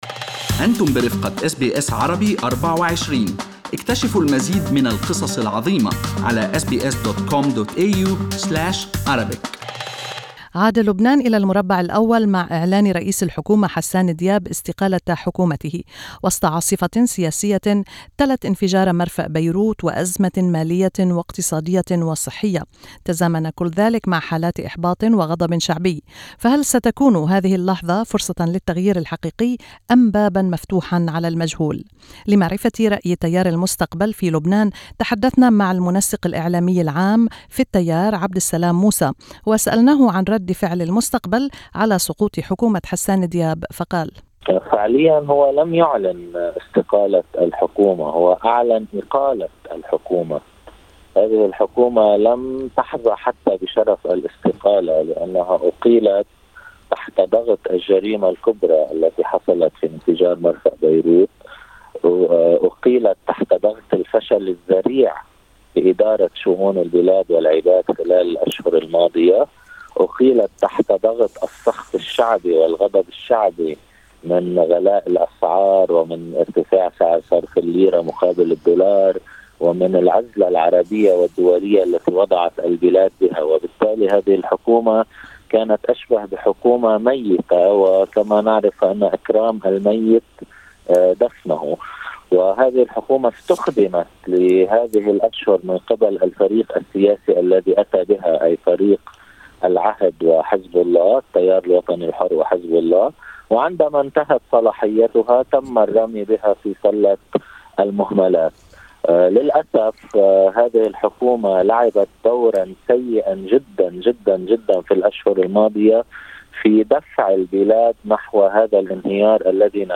في حديث مباشر معه من بيروت